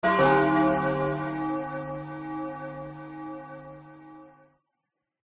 chime.ogg